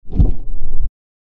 opendoor.mp3